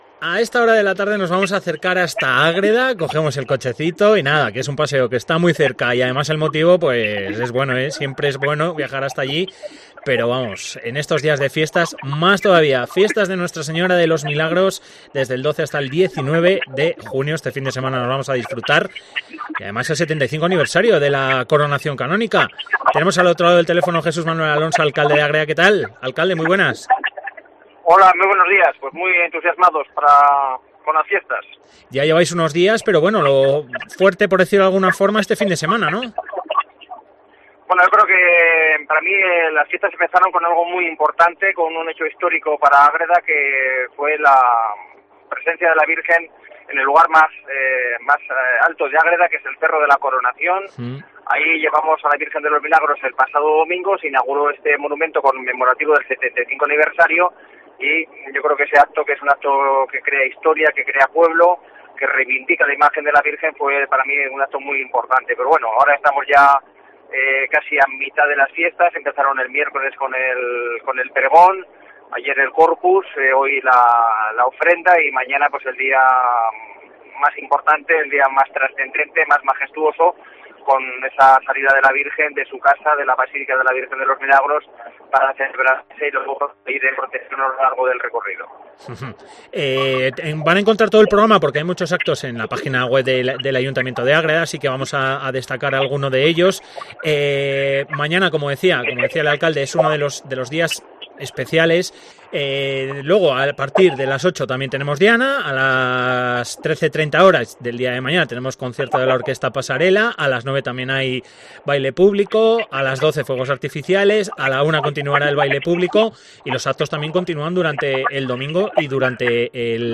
Entrevista Jesús Manuel Alonso. Alcalde de Ágreda. Fiestas de nuestra señora de los Milagros.